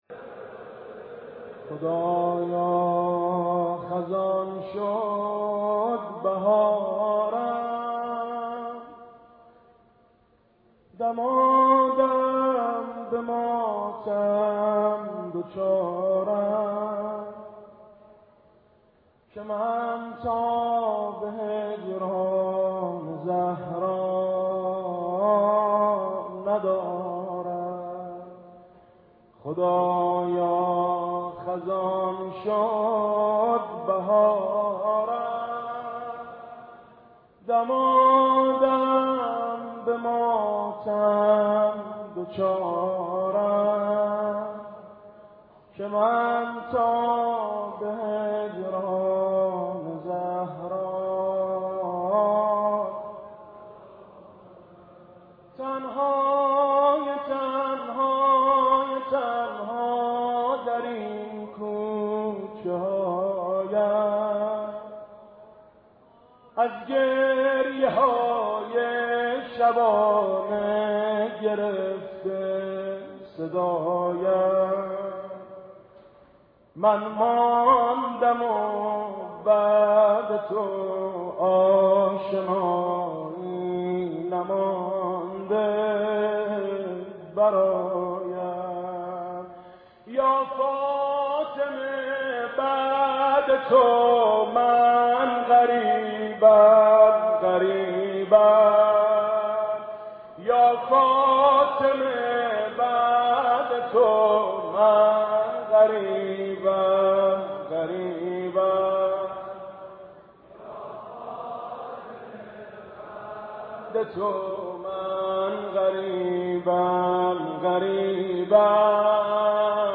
خدایا خزان شد بهارم - مداحی میثم مطیعی - فاطمیه 89 - بیت رهبری
این مداحی مربوط به شب چهارم فاطمیه سال 89 در حسینیه امام خمینی (ره) و در حضور رهبر معظم انقلاب است.